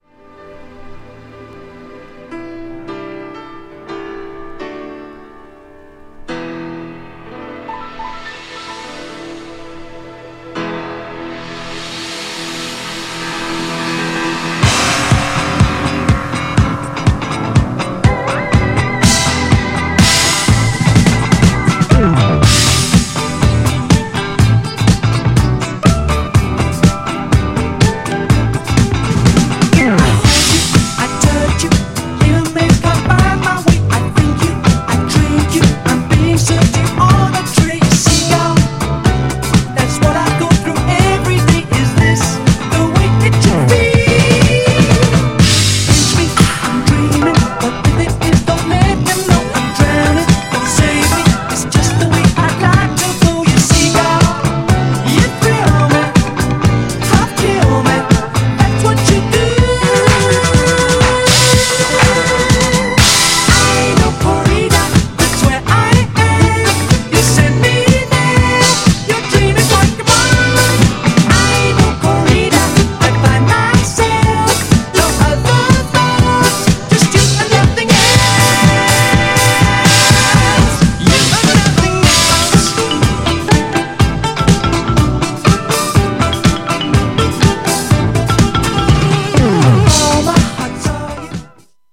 ３曲ともGARAGE & DISCO CLASSIC!!
GENRE Dance Classic
BPM 116〜120BPM